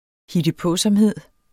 Udtale [ hidəˈpɔˀsʌmˌheðˀ ]